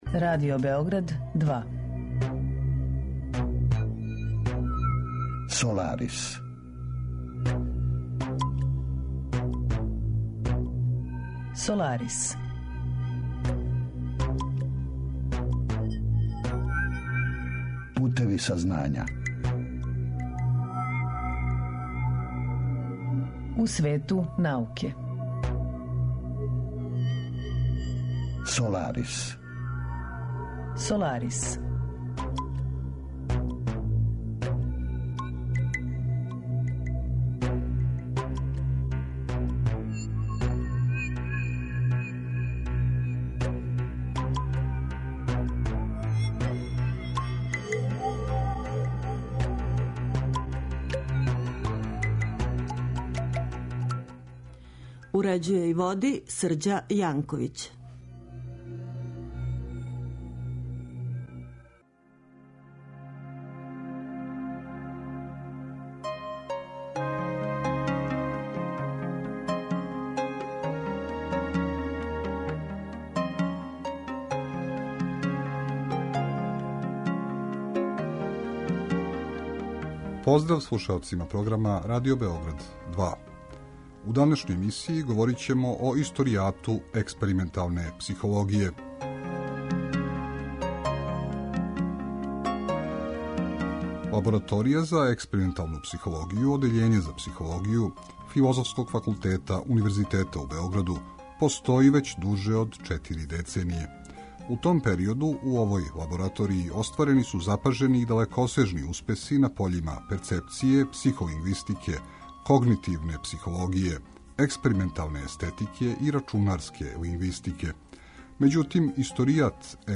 Саговорник